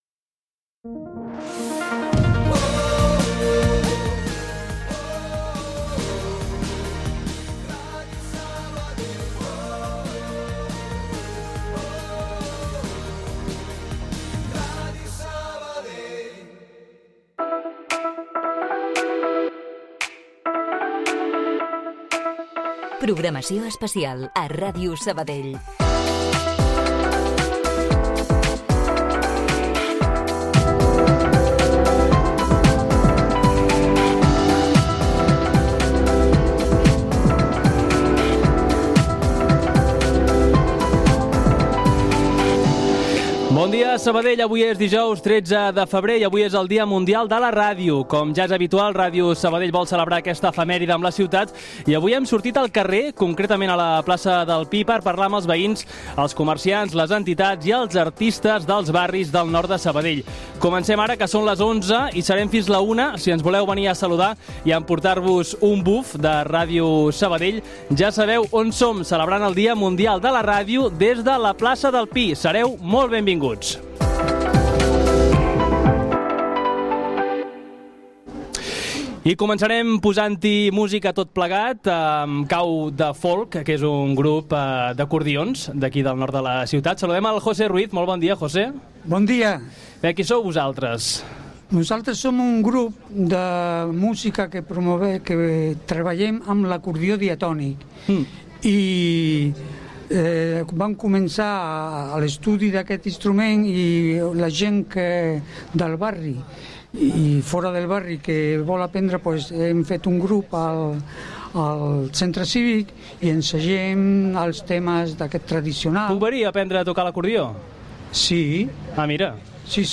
Programes especials RàdioSabadell celebra el Dia Mundial de la Ràdio des de la plaça del Pi play stop repeat mute max volume